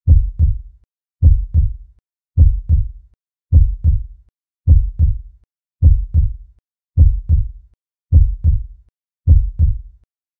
Beating Heart Sound Effect
A realistic beating heart sound effect delivers deep, steady pulses that feel natural and lifelike.
Audio loop.
Beating-heart-sound-effect.mp3